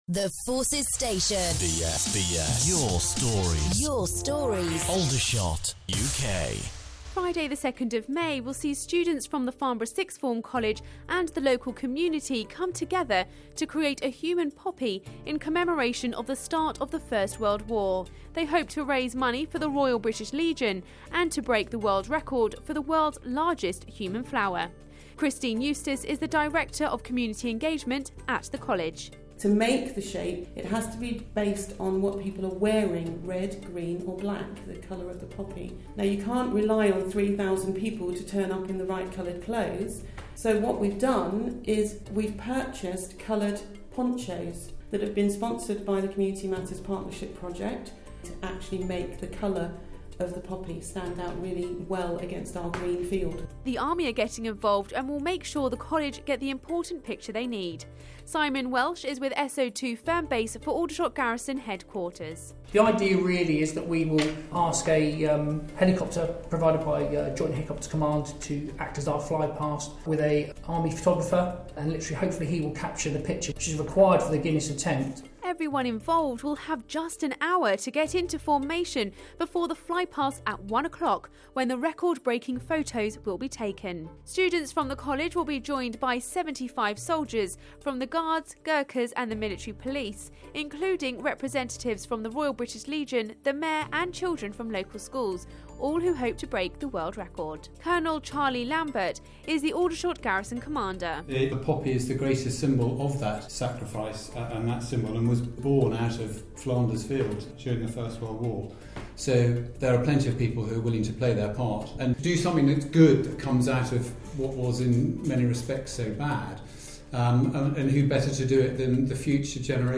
They hope to raise money for the Royal British Legion and to break the world record. Our reporter